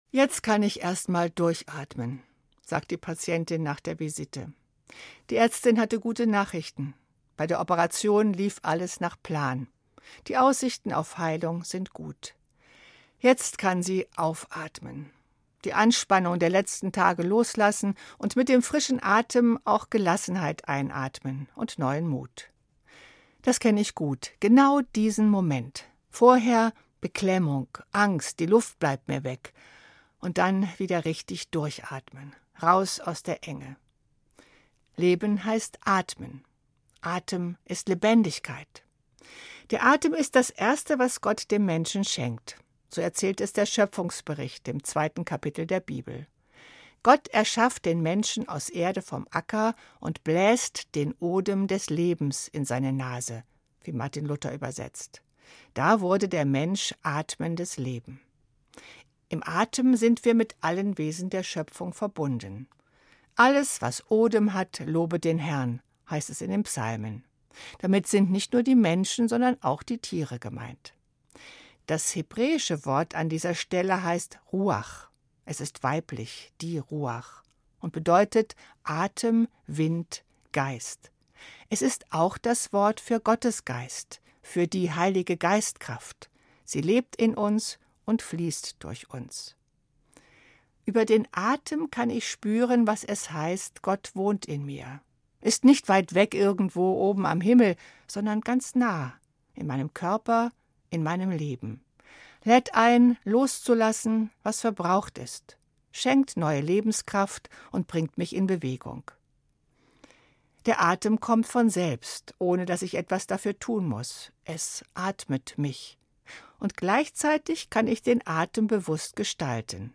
Evangelische Pfarrerin, Marburg